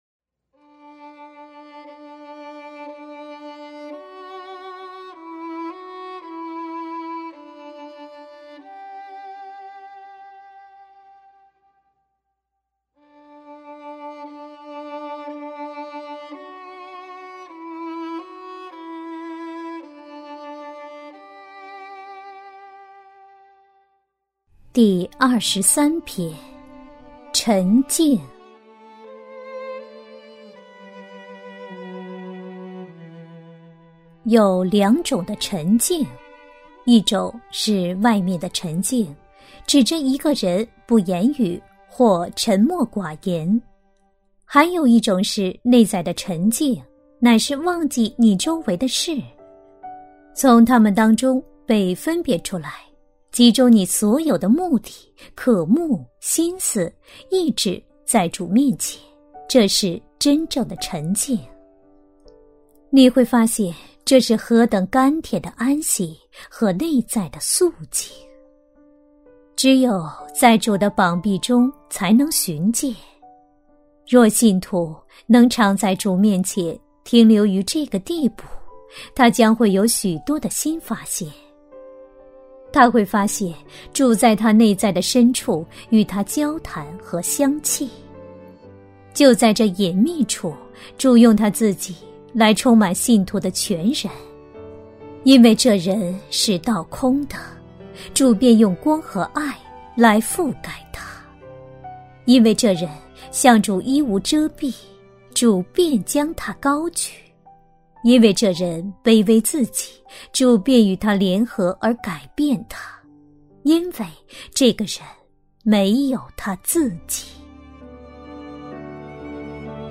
首页 > 有声书 | 灵性生活 | 灵程指引 > 灵程指引 第二十三篇：沉静